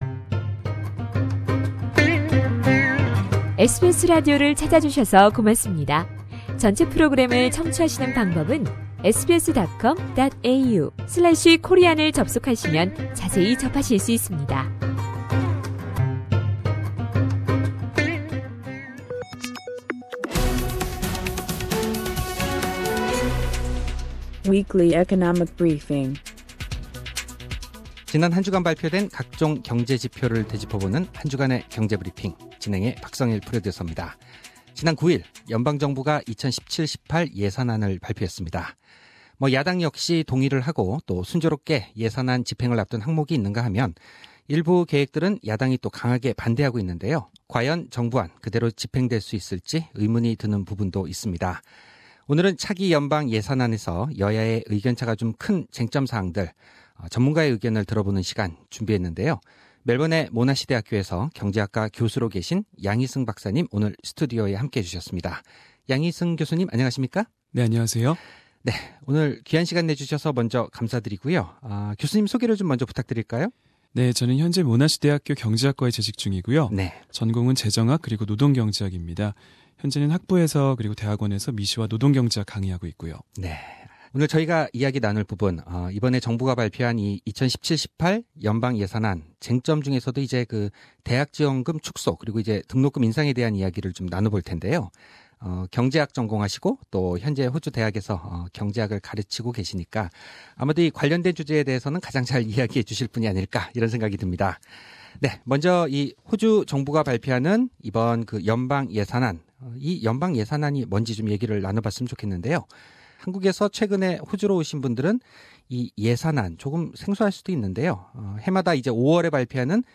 교육 예산 변경, 어떻게 봐야 하나?에 대한 특집 대담을 준비했다.